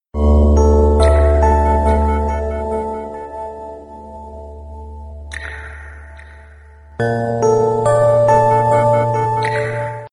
Мелодии на звонок